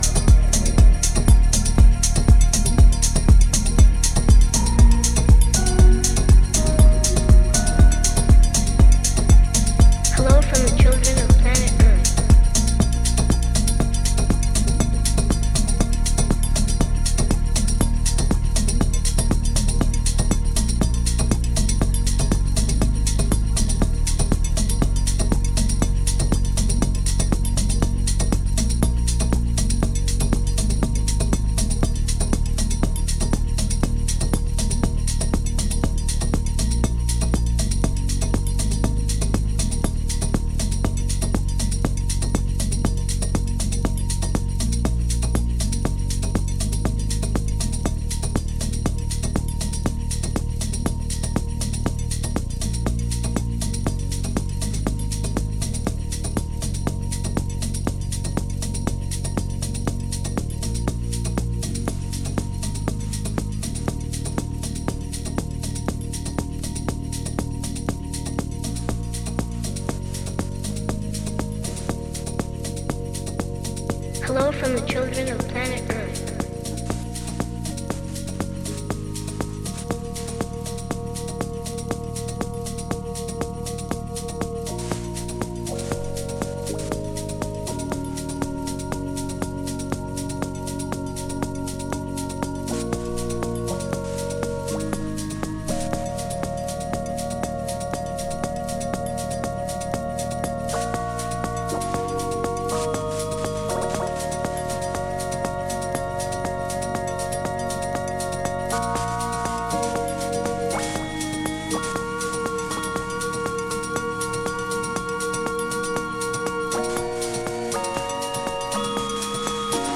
Styl: Progressive, House